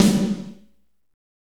Index of /90_sSampleCDs/Northstar - Drumscapes Roland/DRM_Fast Rock/KIT_F_R Kit Wetx